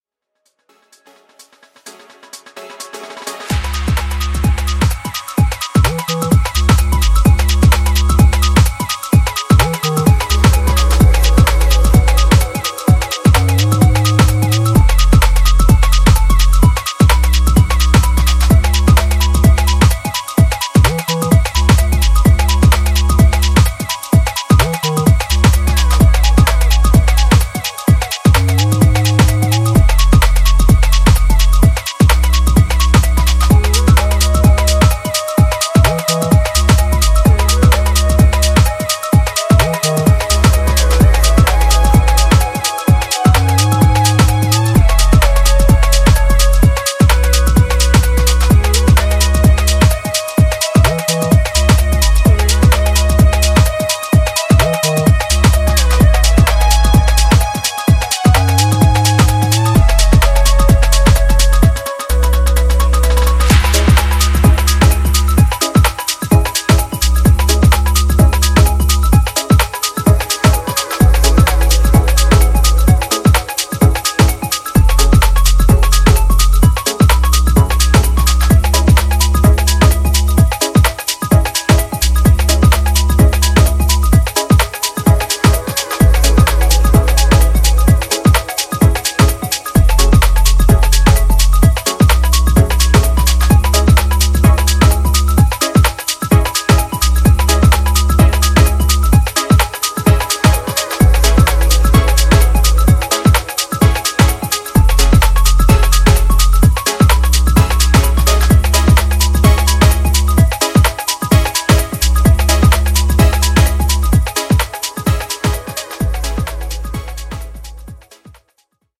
Carrying on a new white label series of killer garage cuts